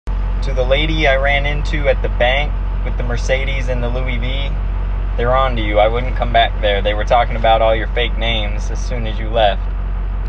Warning Sound Effects Free Download